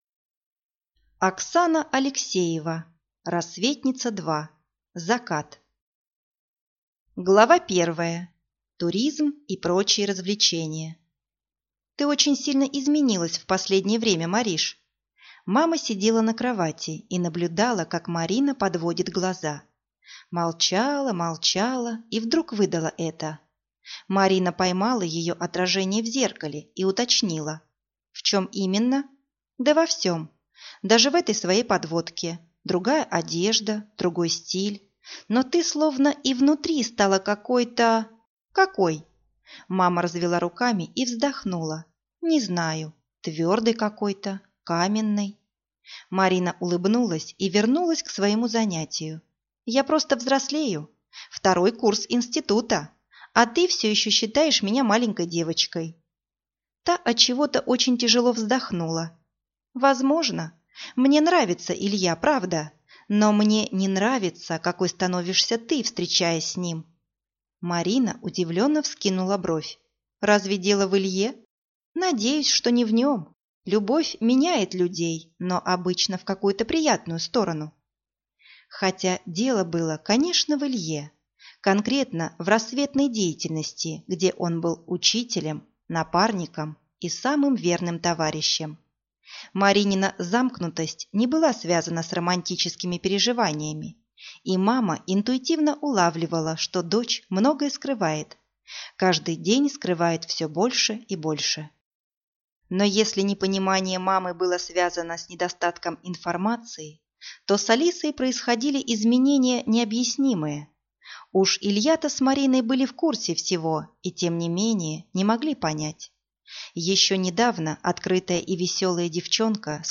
Аудиокнига Рассветница-2: Закат | Библиотека аудиокниг